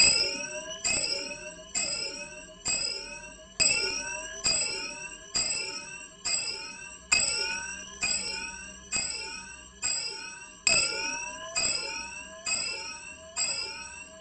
KEtiVqjvMII_alarma.mp3